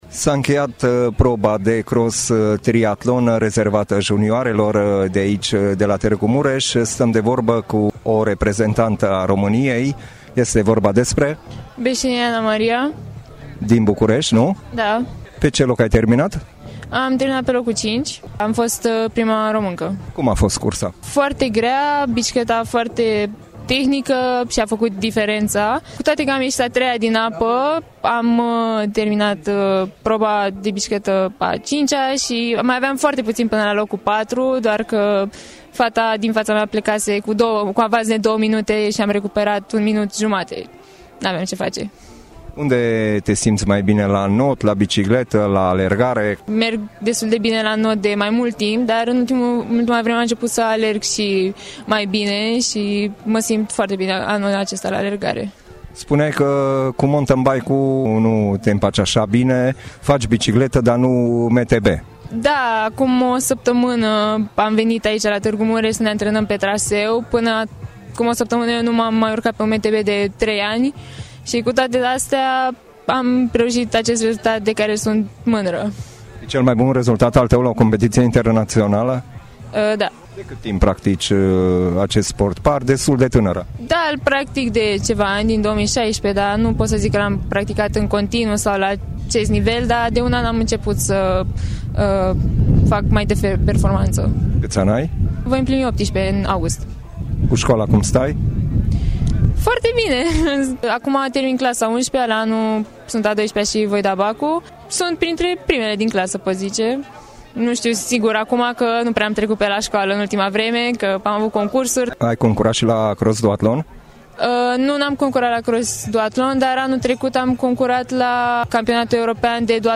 Interviu audio